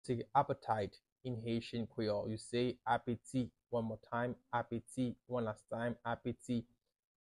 “Appetite” in Haitian Creole – “Apeti” pronunciation by a native Haitian Creole teacher
“Apeti” Pronunciation in Haitian Creole by a native Haitian can be heard in the audio here or in the video below:
How-to-say-Appetite-in-Haitian-Creole-–-Apeti-pronunciation-by-a-native-Haitian-Creole-teacher.mp3